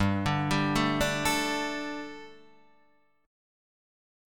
G Suspended 2nd